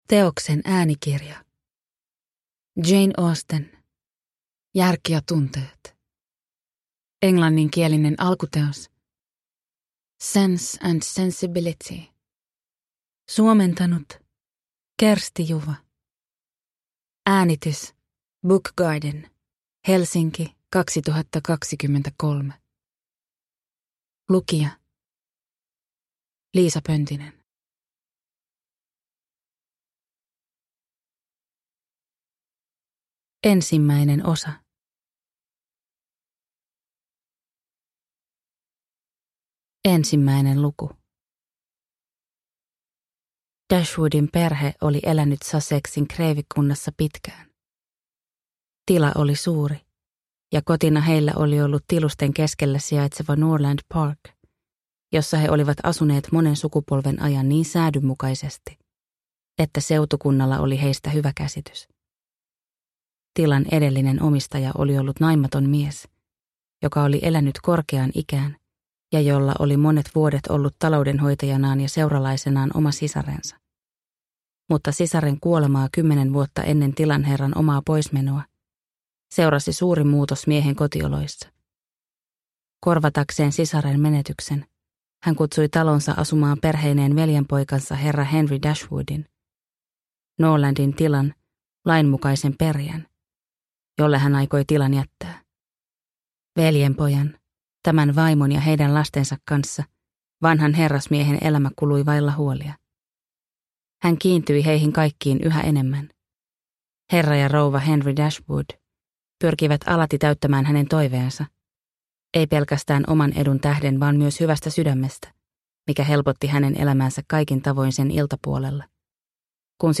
Järki ja tunteet – Ljudbok